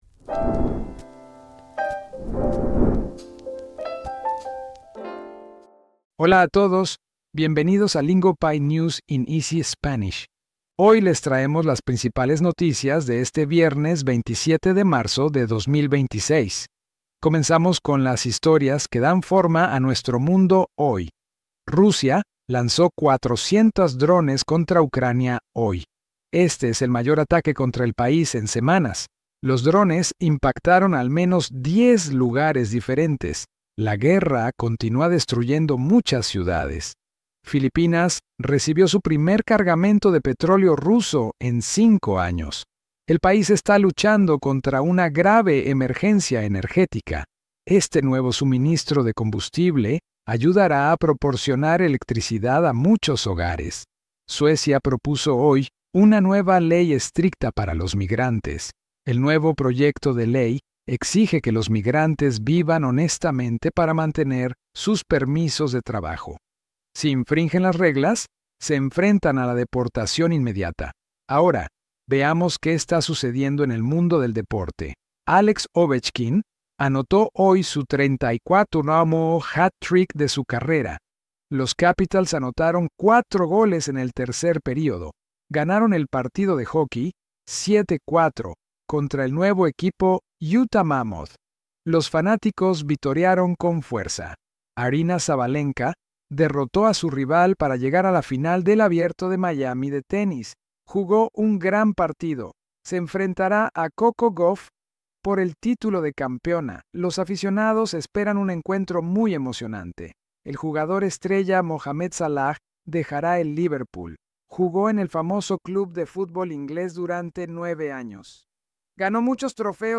Big headlines, slowed down into clear, beginner-friendly Spanish so your listening stays simple.